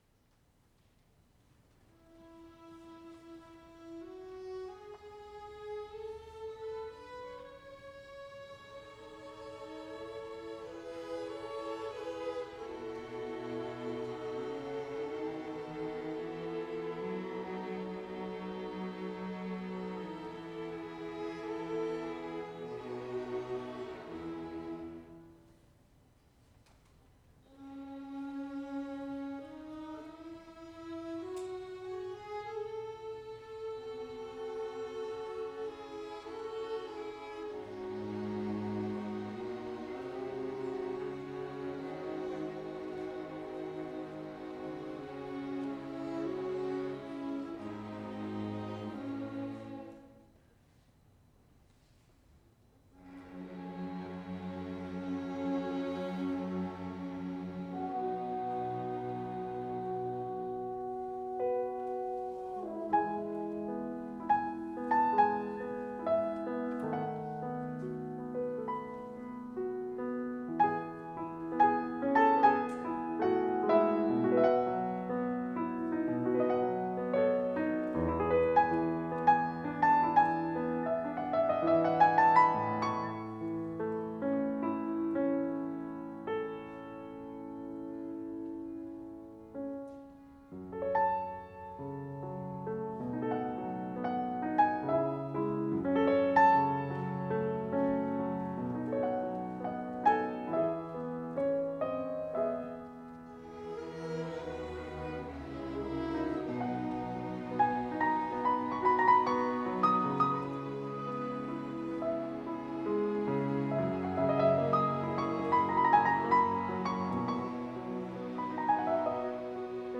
Chopin Piano Concert - Winter 2025 — Nashua Chamber Orchestra